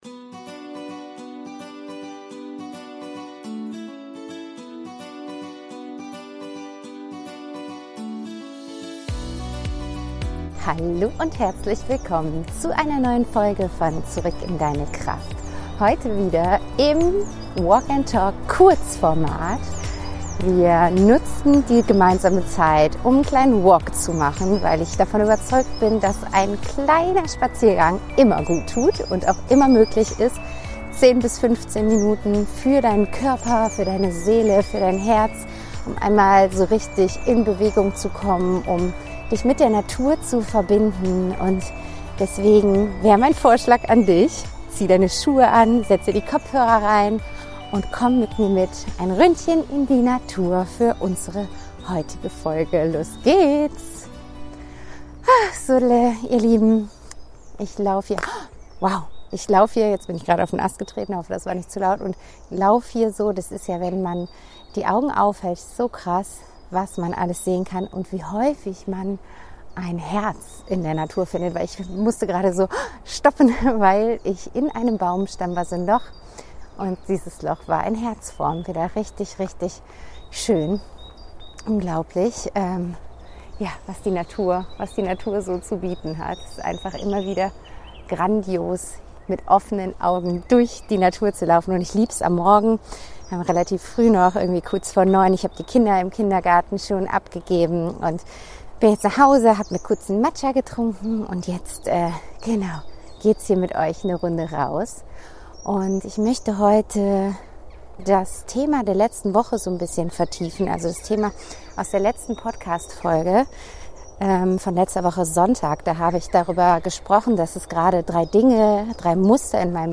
In diesen Folgen nehme ich dich mit auf einen Spaziergang. Beim Gehen teile ich spontane Gedanken, ehrliche Impulse und leise Fragen aus dem Moment heraus – unperfekt in der Tonqualität, dafür nah, authentisch und mitten aus dem Leben.